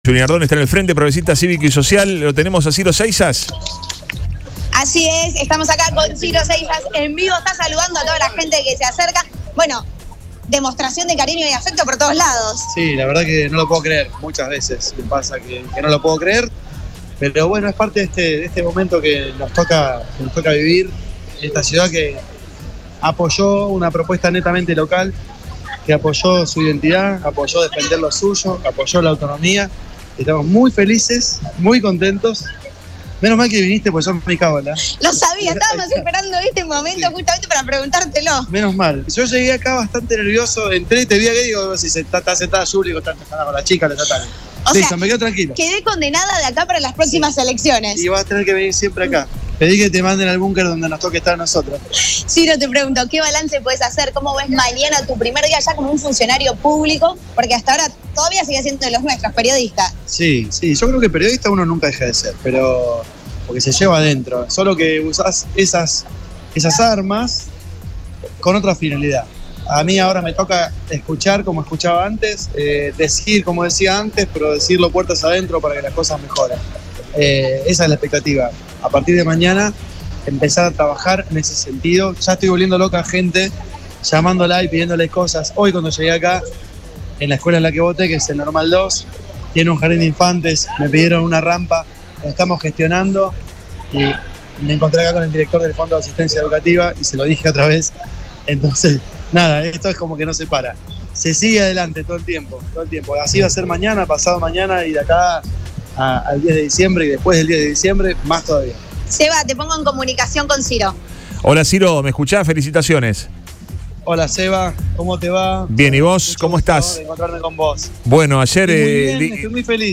En exclusiva para Radio Boing manifestó;  “Estamos muy felices y muy contentos. Los ciudadanos manifestaron la necesidad de autonomía”.